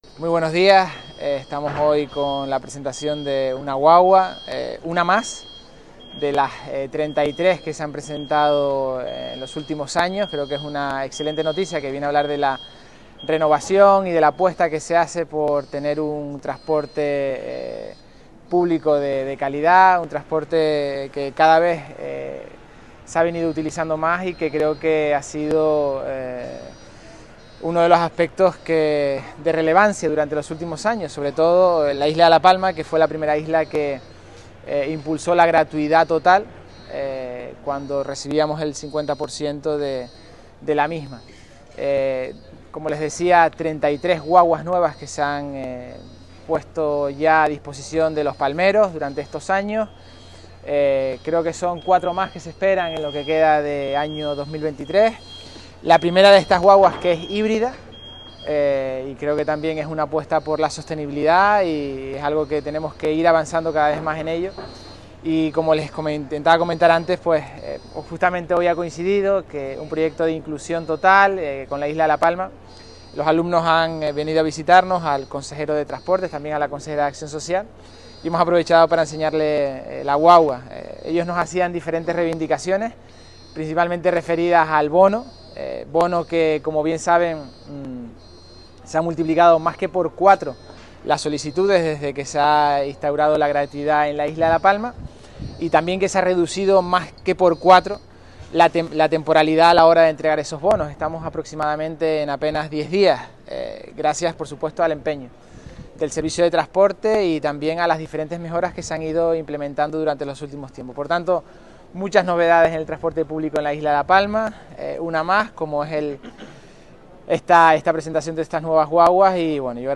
Declaraciones audio Mariano Zapata guaguas.mp3